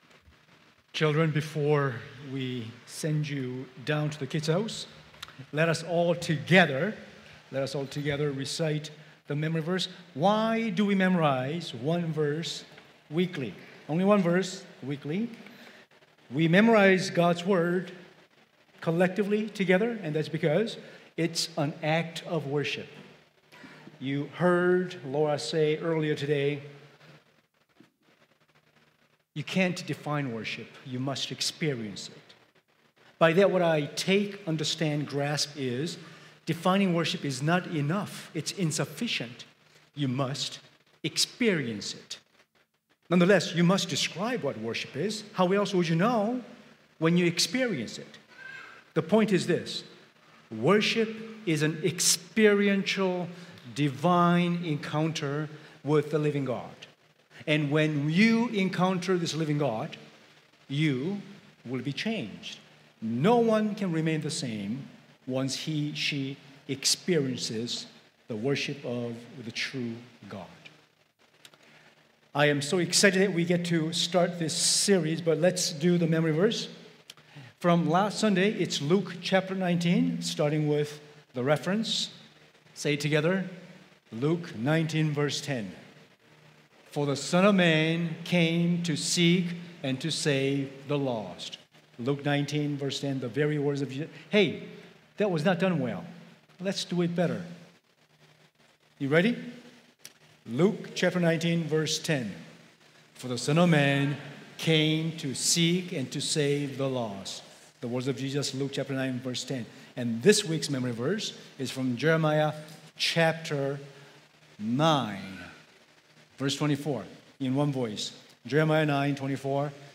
Sermons | mosaicHouse